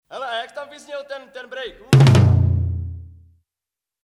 Nahrávací studio v Lipově audio / digital